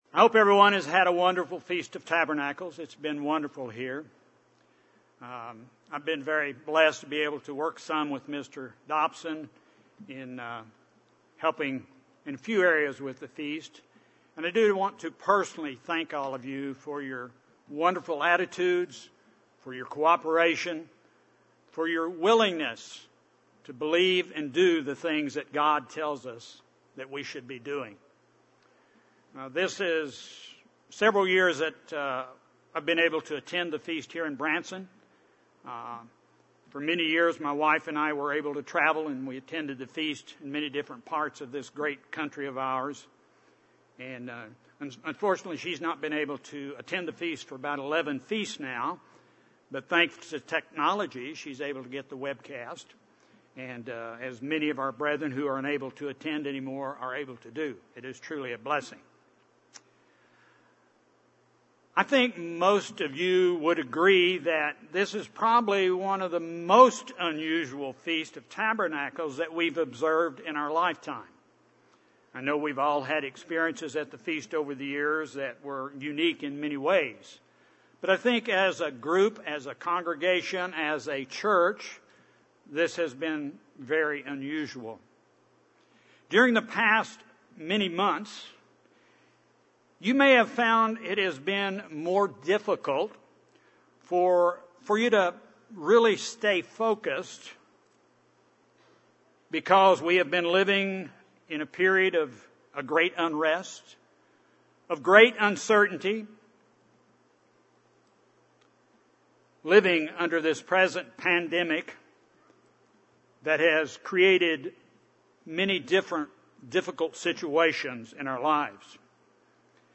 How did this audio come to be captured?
This sermon was given at the Branson, Missouri 2020 Feast site.